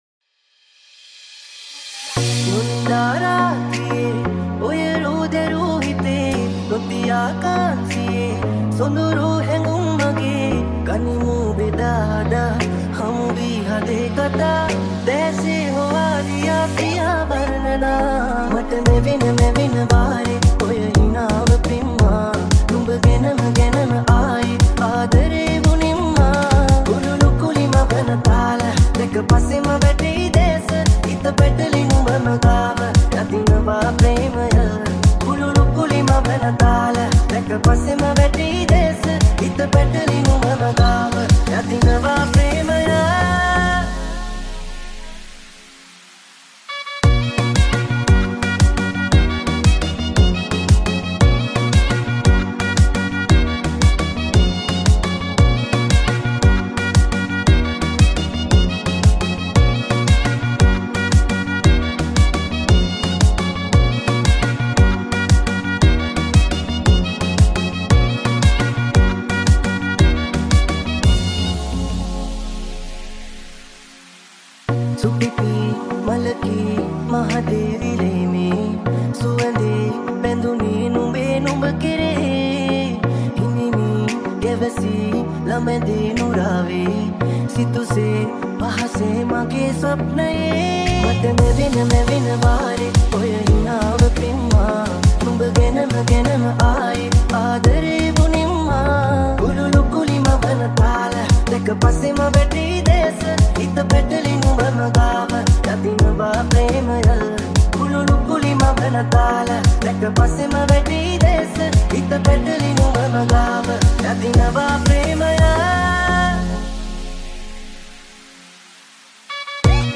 High quality Sri Lankan remix MP3 (3.4).